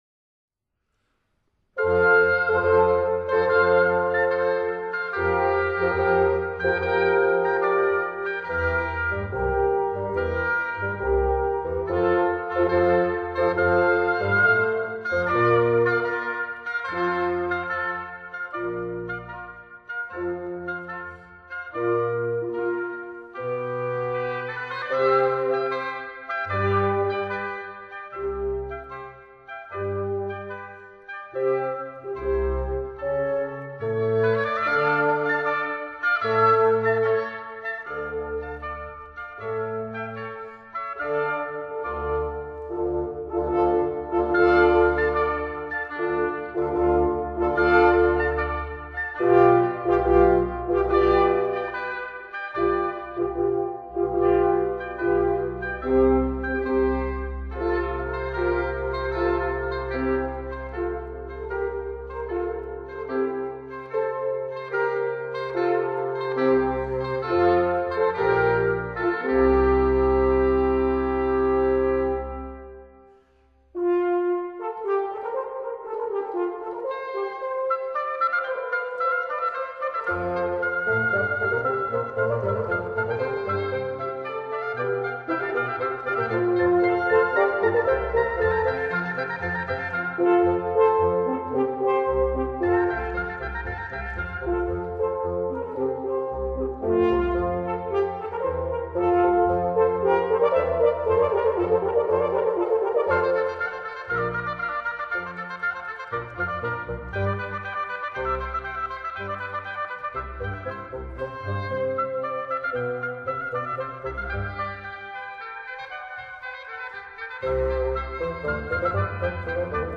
suite for 2 oboes, 2 horns & continuo in F major